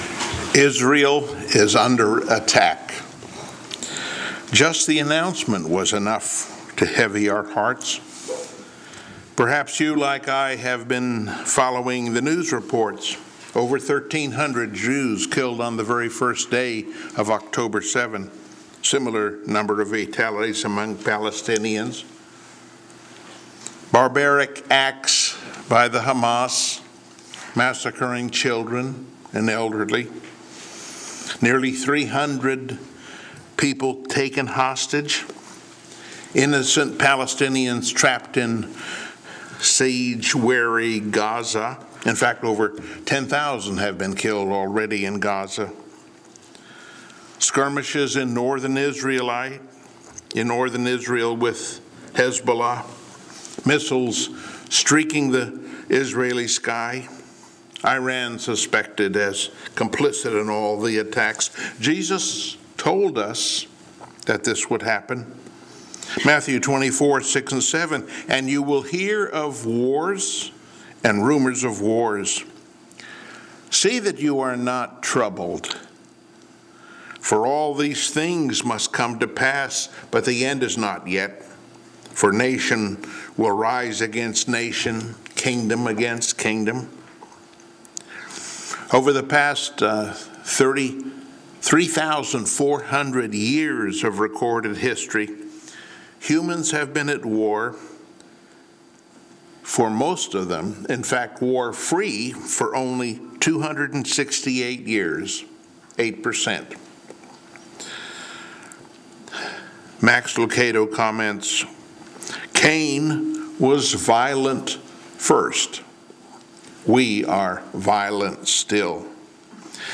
Service Type: Sunday Morning Worship Topics: Humble Gratitude , Unseen Angels , Your Bethel « “Grace Will Bring Us Home” “Courageous Joy” »